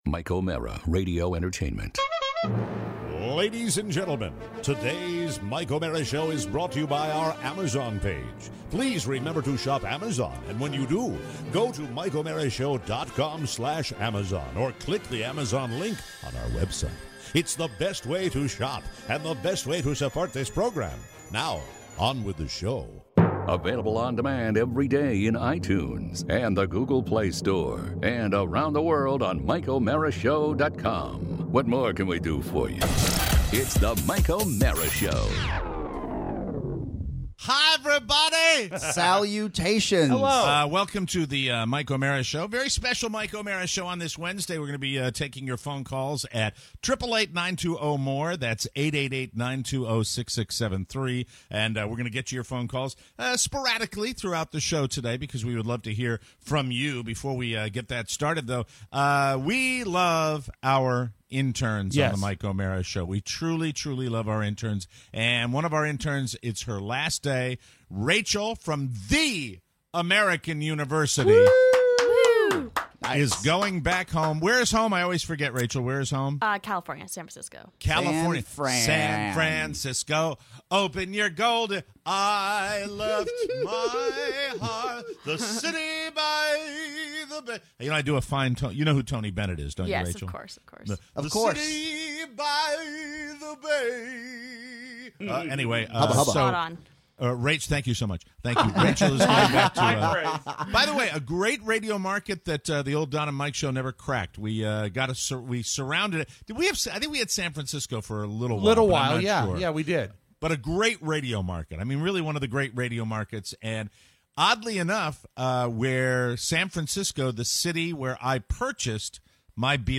Plus… your phone calls. Aborted landings!